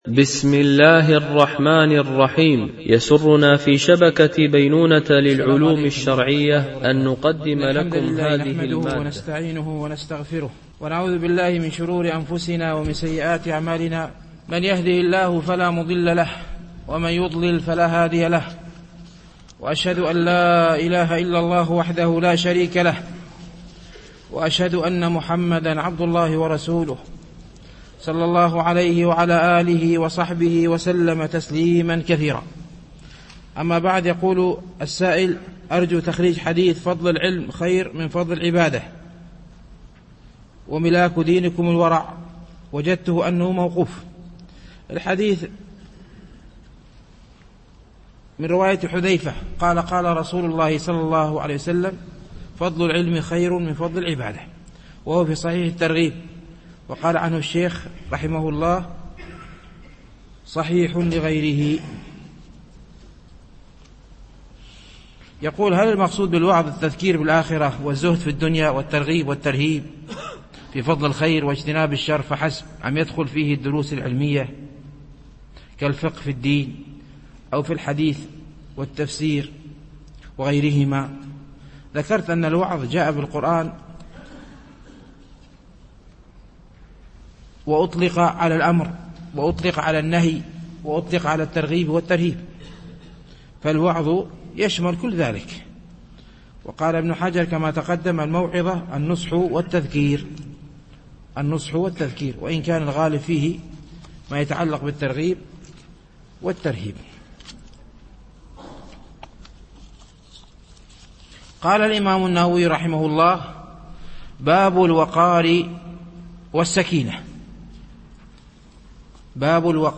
شرح رياض الصالحين - الدرس 193 (الحديث 703 - 705)